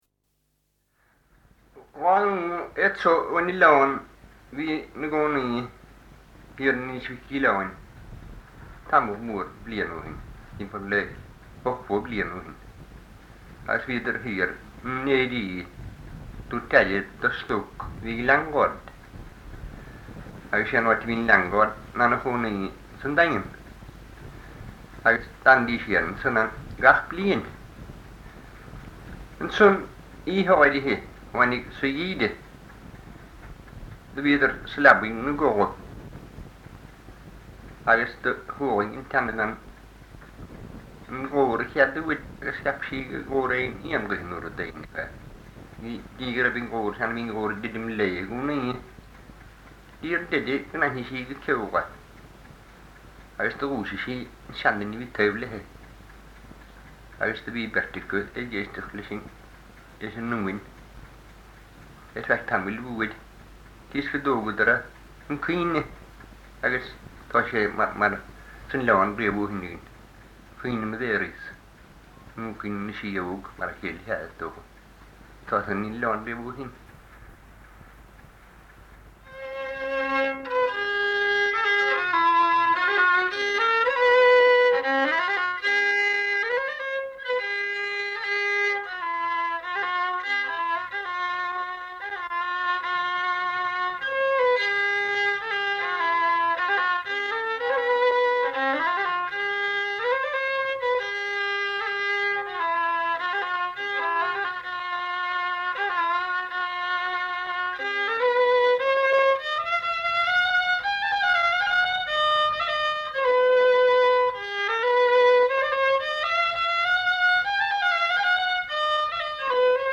Here is the recording from the Blasket island CD, Beauty an Oileáin .